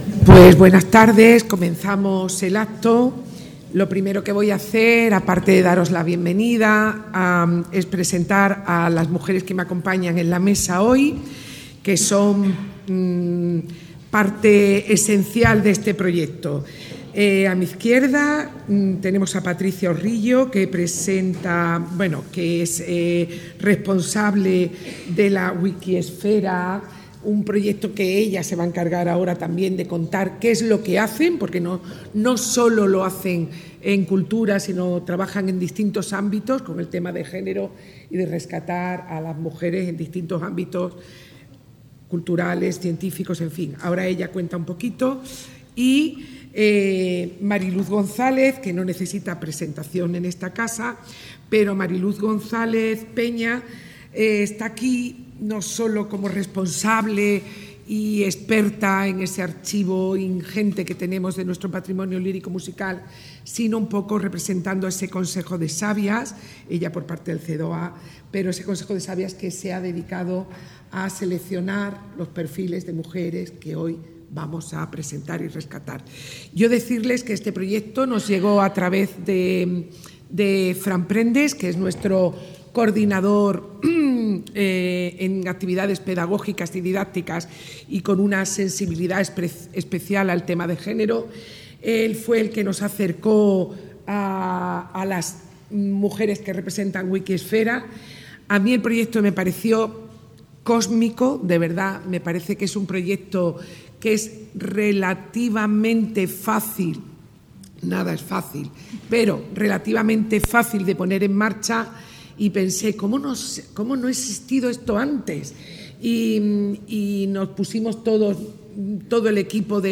Presentación de «Mujeres de Zarzuela»